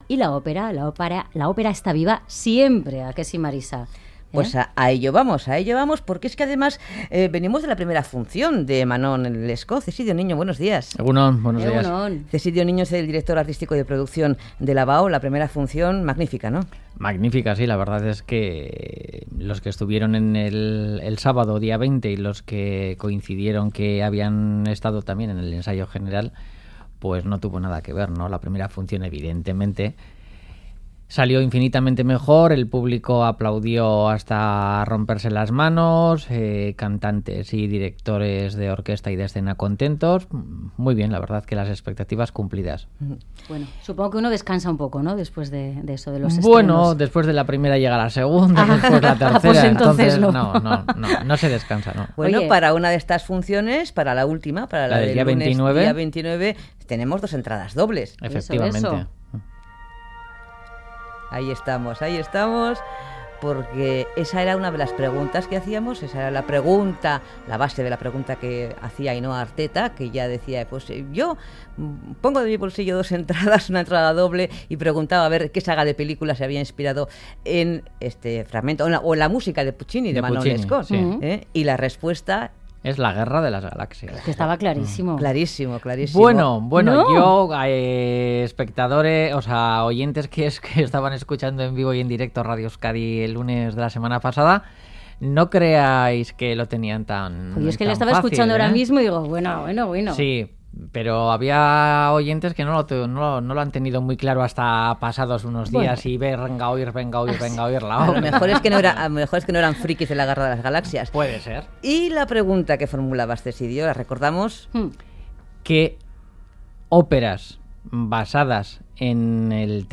Más voces masculinas: barítono y bajo